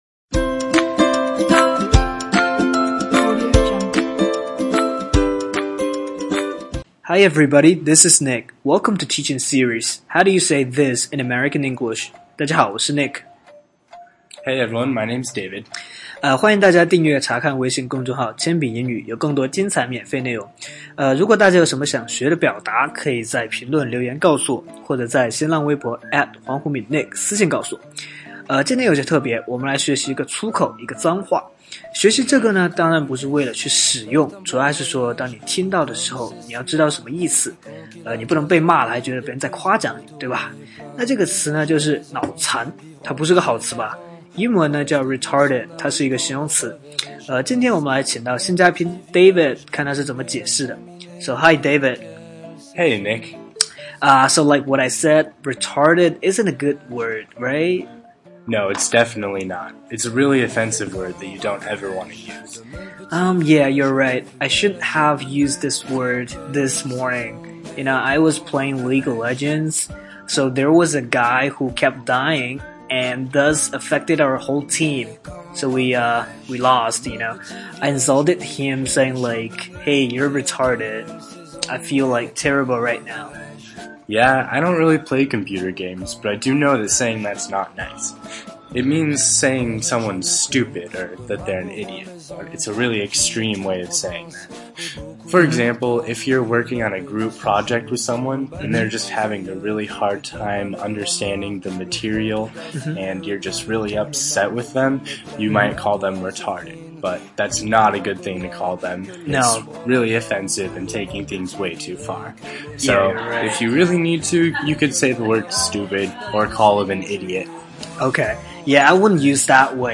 在线英语听力室全网最酷美语怎么说:第32期 脑残的听力文件下载, 《全网最酷美语怎么说》栏目是一档中外教日播教学节目，致力于帮大家解决“就在嘴边却出不出口”的难题，摆脱中式英语，学习最IN最地道的表达。